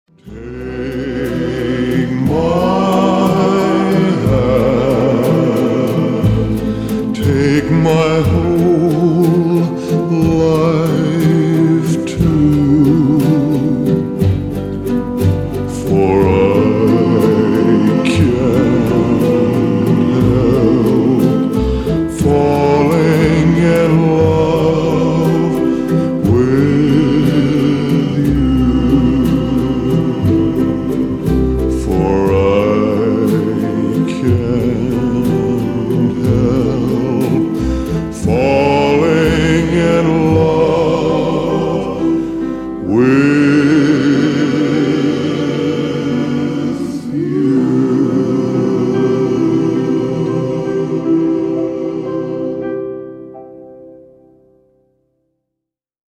• Качество: 320, Stereo
гитара
мужской вокал
Cover
пианино
медленные
хор
баллада
ретро
кантри